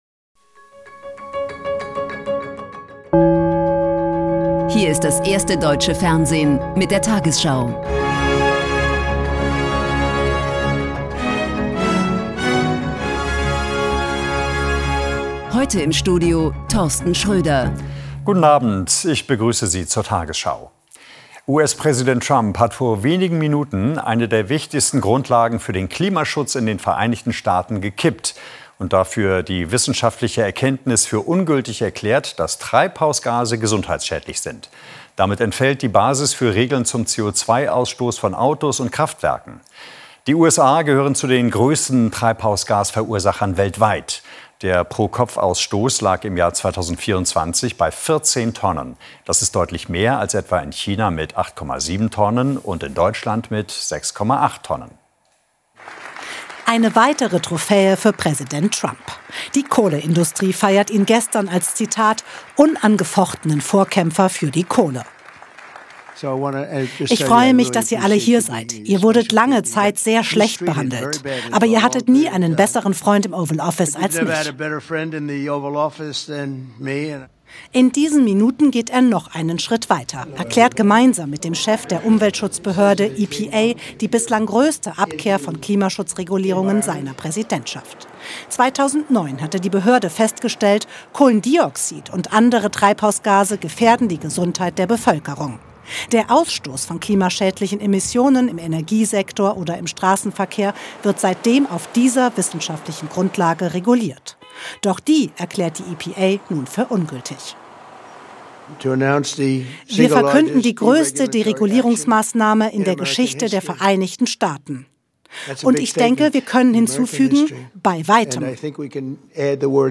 tagesschau 20:00 Uhr, 12.02.2026 ~ tagesschau: Die 20 Uhr Nachrichten (Audio) Podcast